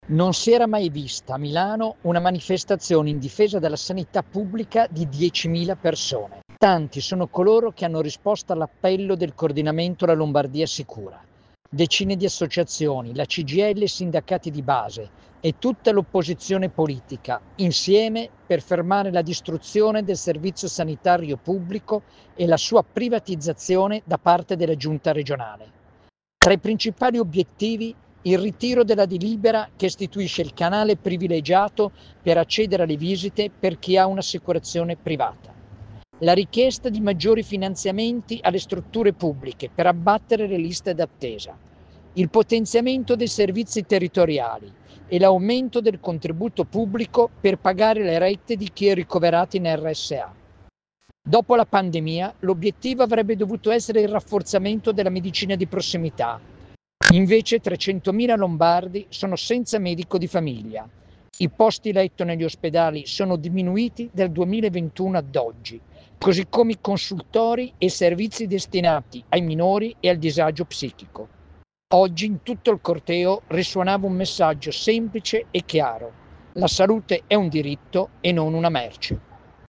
Il racconto di Vittorio Agnoletto, tra i promotori dell'iniziativa